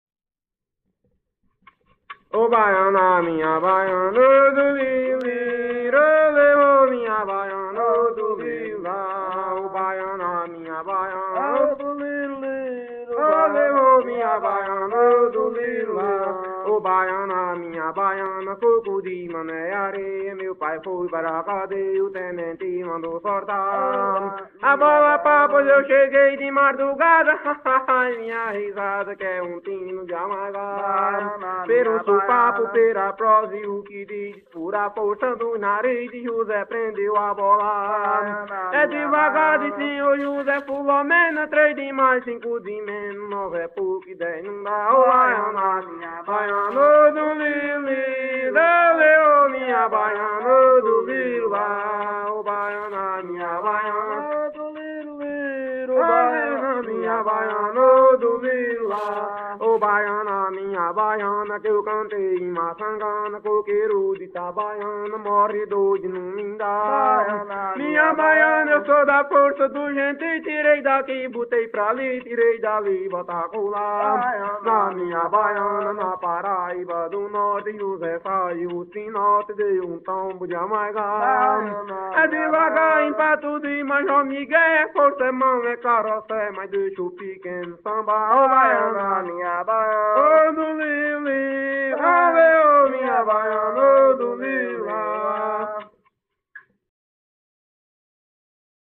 Coco embolada – “”Baiana. minha baiana””.